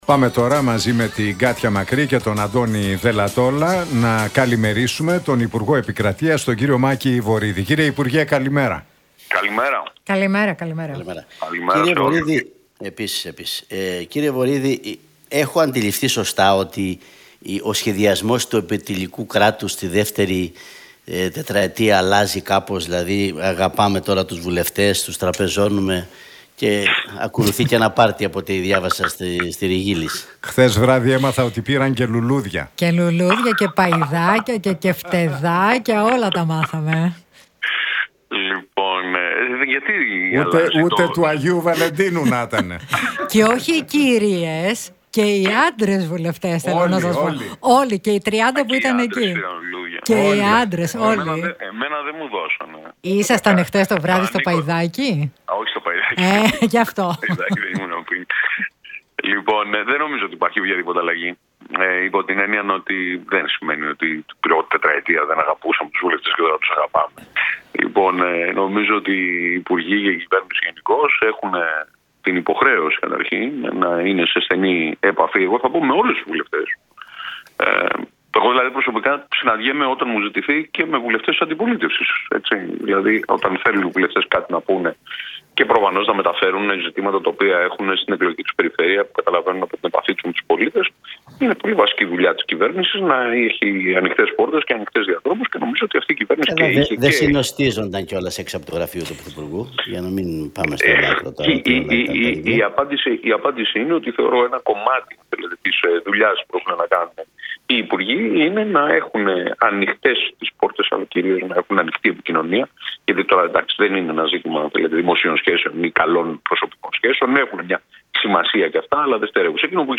Δεν σημαίνει ότι την πρώτη 4ετία δεν αγαπούσαμε τους βουλευτές και τώρα τους αγαπάμε» τόνισε μεταξύ άλλων ο Μάκης Βορίδης μιλώντας στον Realfm 97,8 και τους Νίκο Χατζηνικολάου